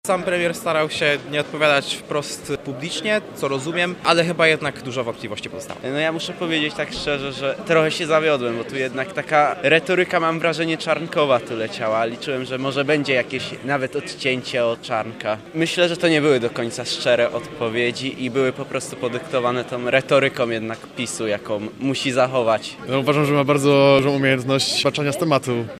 Były premier podczas otwartego spotkania przez prawie dwie godziny odpowiadał na pytania w wypełnionej po brzegi auli wydziału.